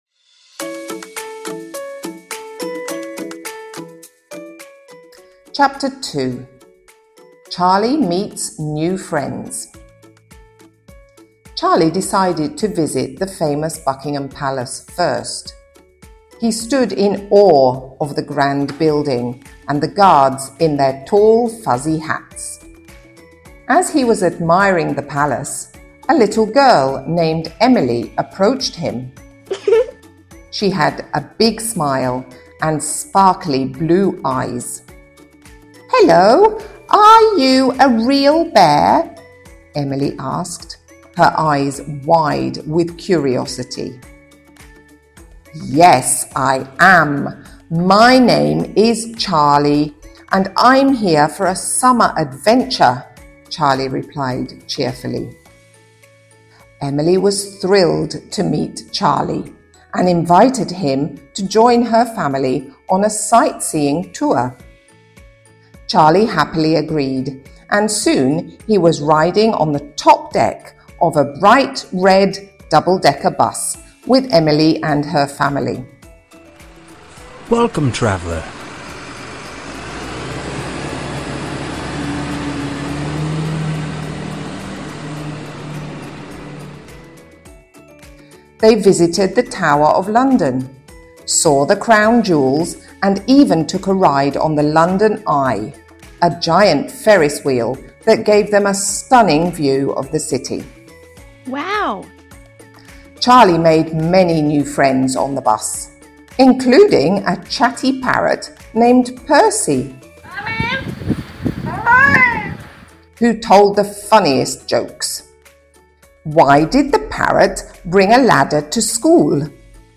sound effects from Pixabay